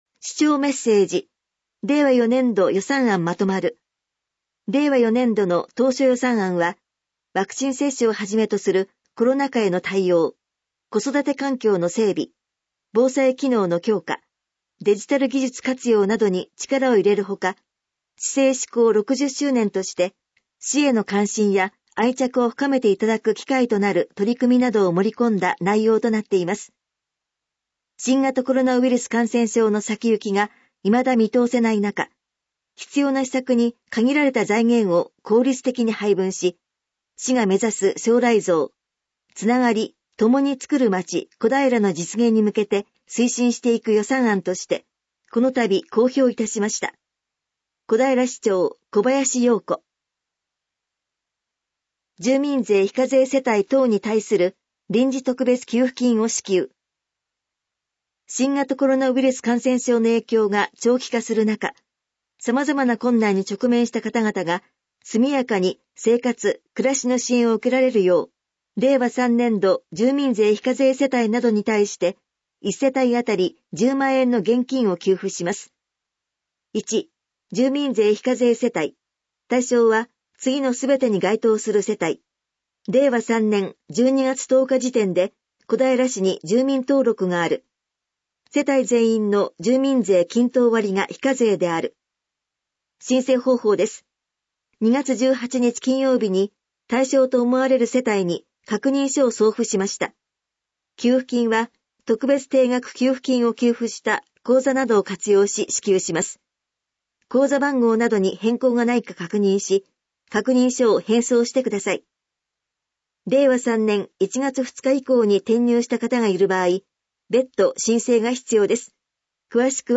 市長メッセージ